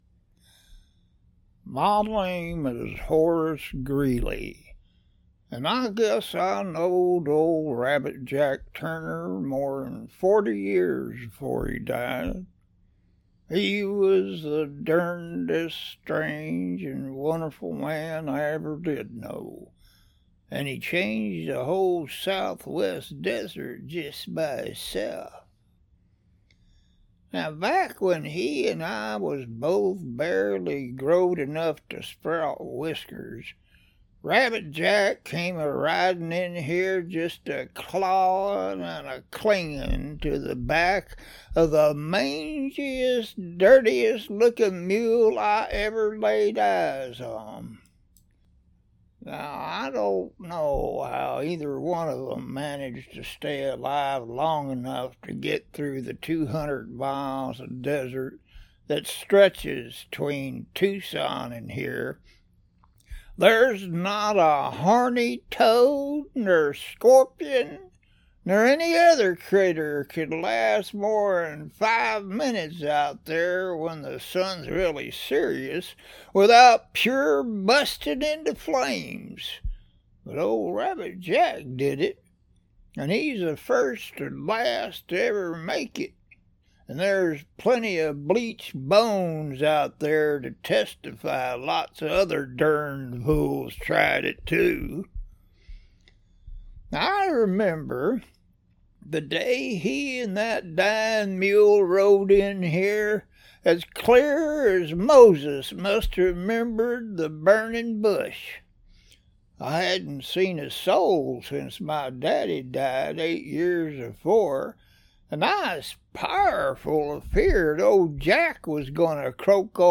He speaks directly to the audience, in a rough, slow Southwestern drawl.